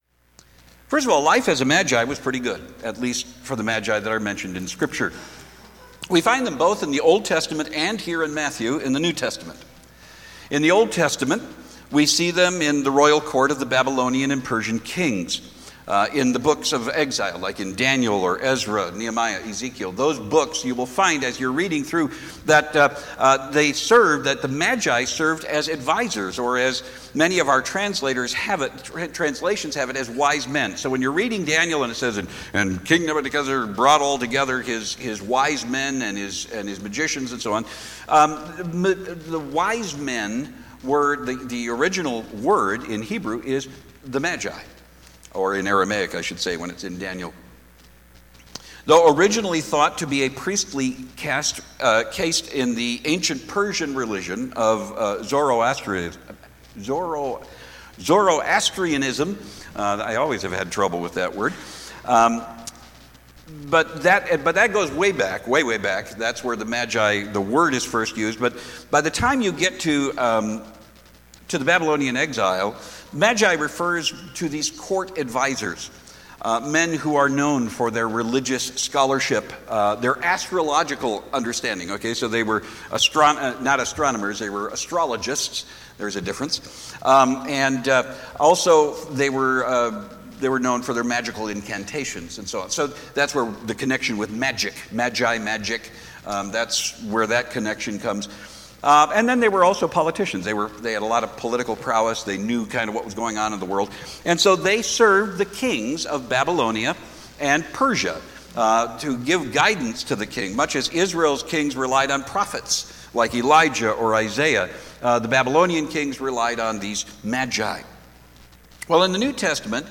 Sermons | The Rock of the C&MA